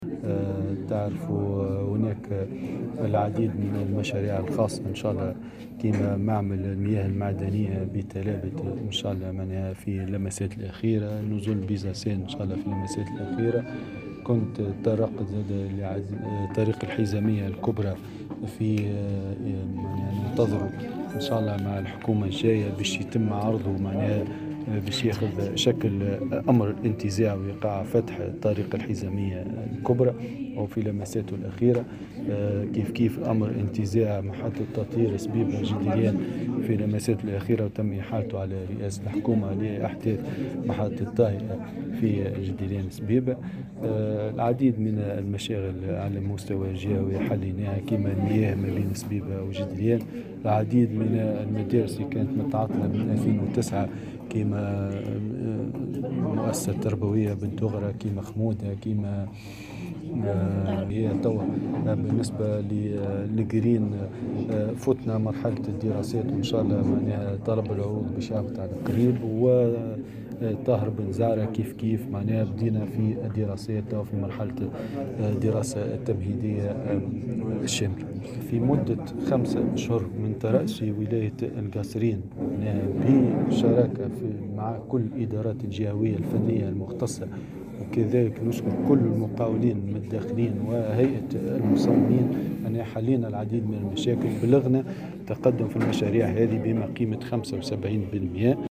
تطرق والي الجهة محمد شمسة صباح اليوم 06 جانفي 2020 الى المشاكل التنموية  بالجهة خلال ندوة صحفية اشرف عليها بمركز الولاية اثر طلب من هيئة مهرجان الشهيد للحديث عن تطور انجاز المشاريع و الاستثمار بالجهة.